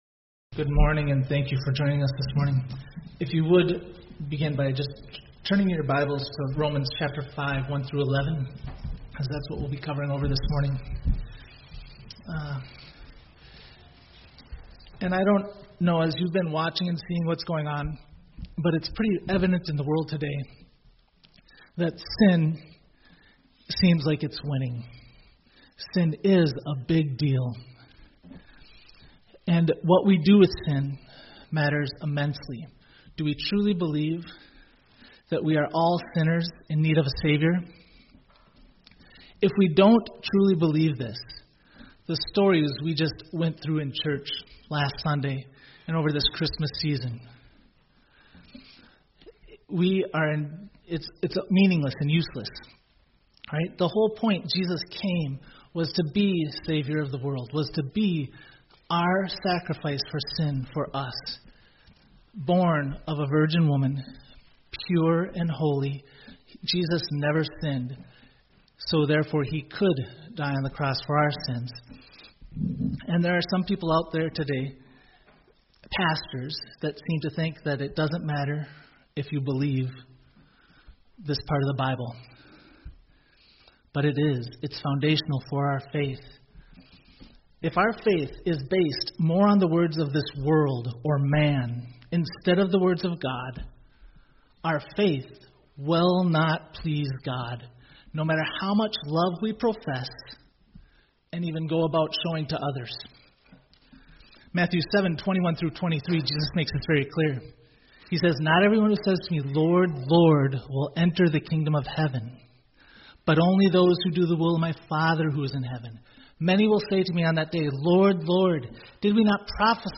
2025 Sermons | Eureka Baptist Church